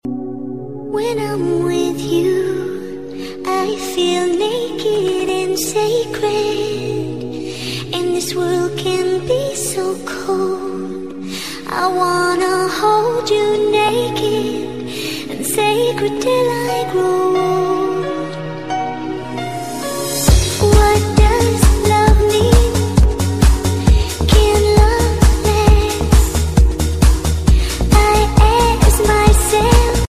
Dance & Trance